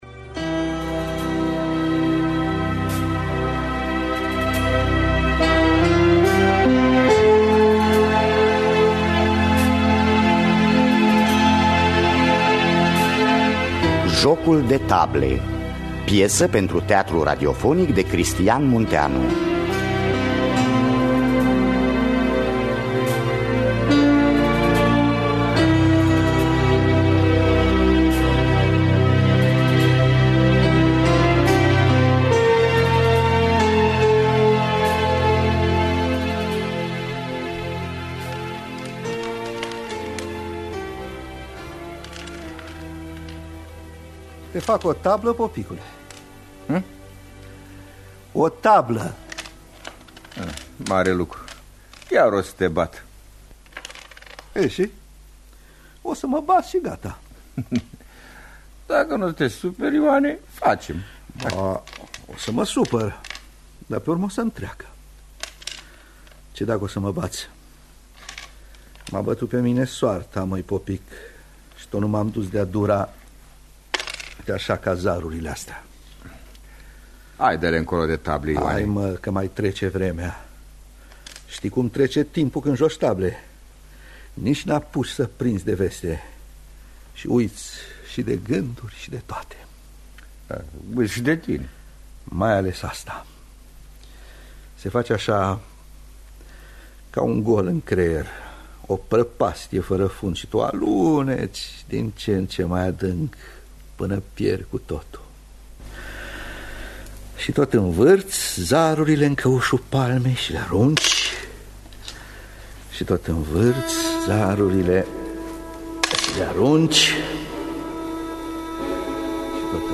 Teatru Radiofonic Online